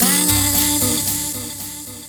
Ala Brzl 2 Vox Intlude-B.wav